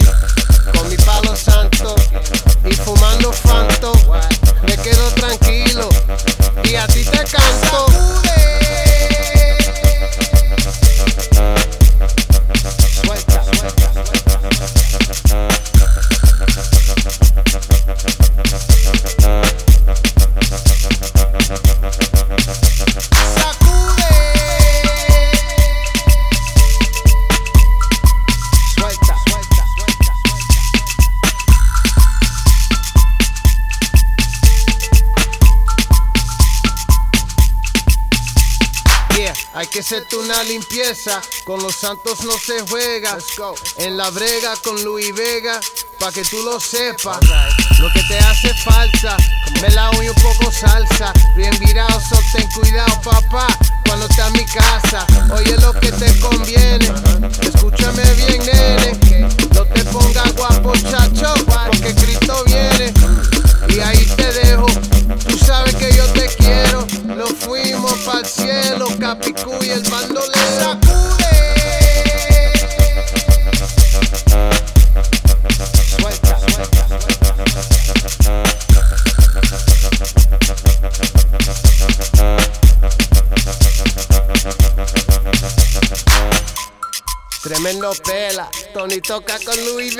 ジャンル(スタイル) DEEP HOUSE / AFRO HOUSE